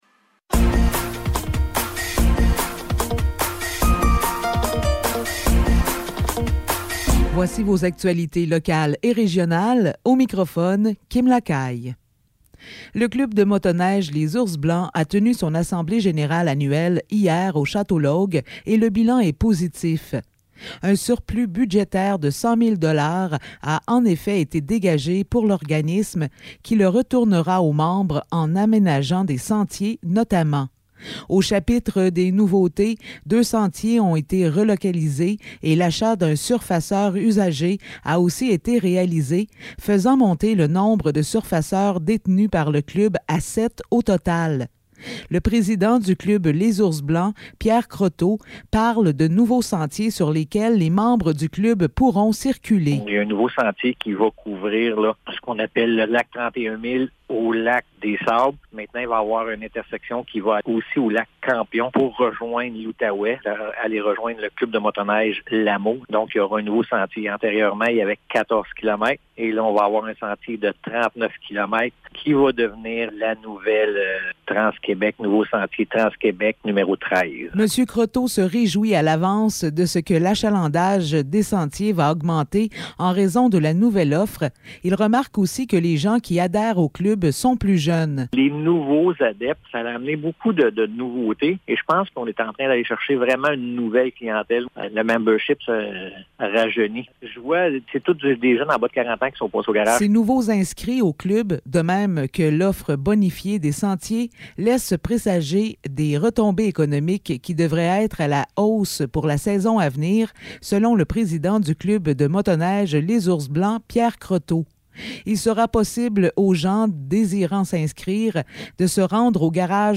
Nouvelles locales - 9 décembre 2022 - 12 h